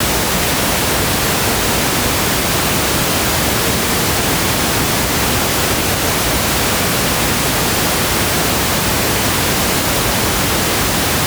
rosa Rauschen - Presonus HP2
KABELGEBUNDEN
Da ich den Unterschied zwischen Funk und kabelgebundenen Systemen aber genau wissen wollte, habe ich jedes System mit rosa Rauschen beschickt und den Kopfhörerausgang wieder aufgenommen.
rosa-rauschen-Presonus-HP2.wav